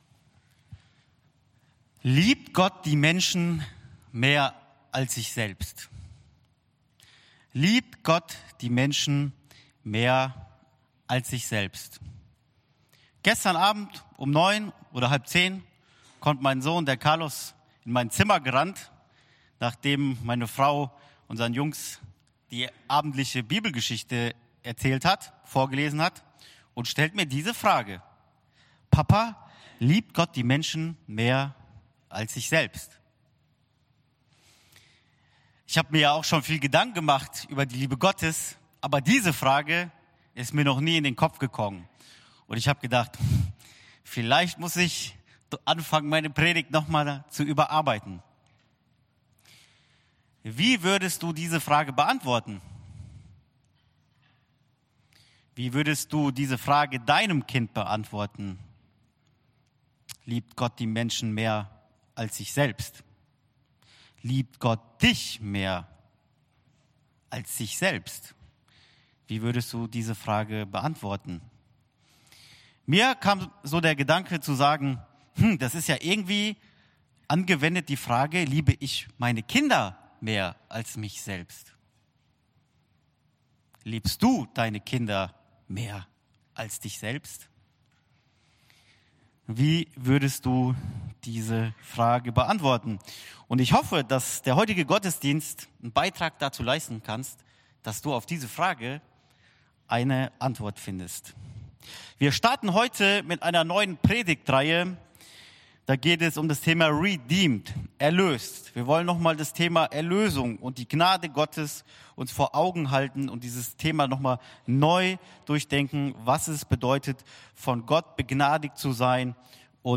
Re:search (erkunden) – Warum auch die Guten verloren sind ~ EFG-Haiger Predigt-Podcast Podcast